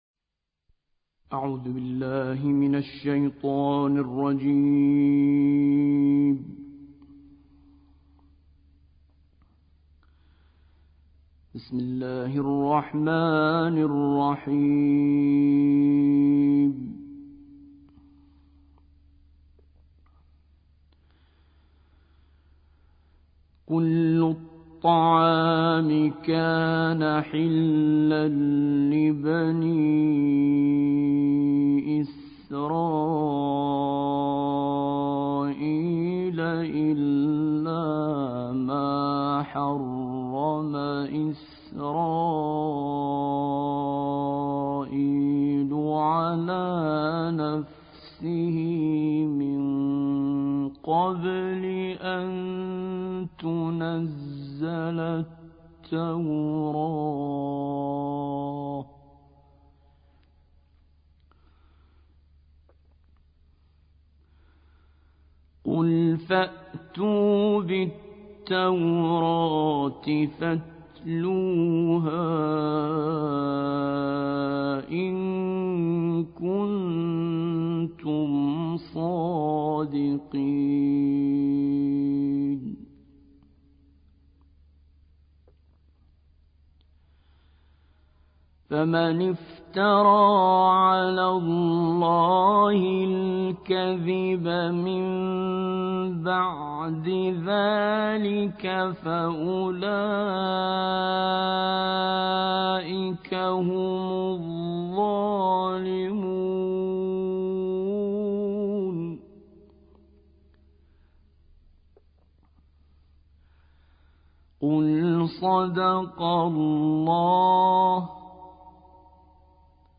دانلود قرائت سوره آل عمران آیات 93 تا 109